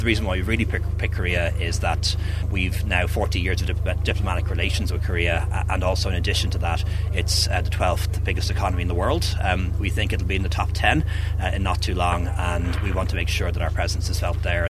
Leo Varadkar says this is a new approach, with a different country to be chosen each year: